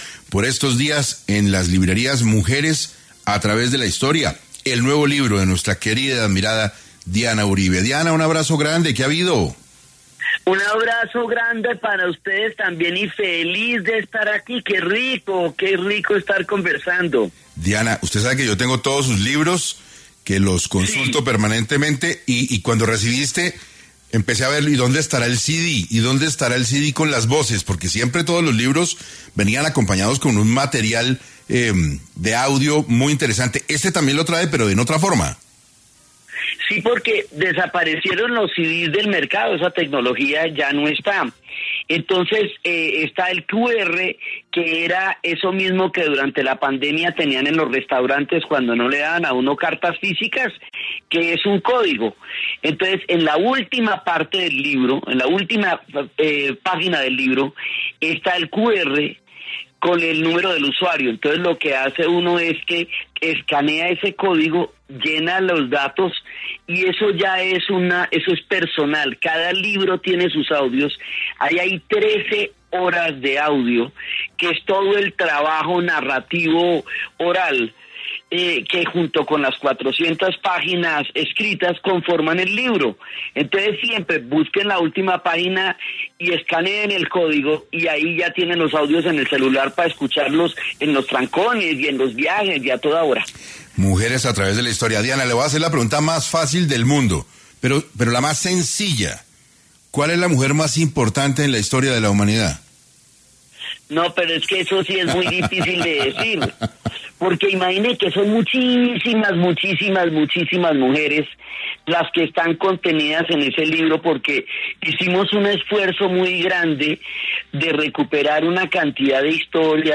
En Caracol Radio estuvo Diana Uribe, historiadora, conversando sobre su nuevo libro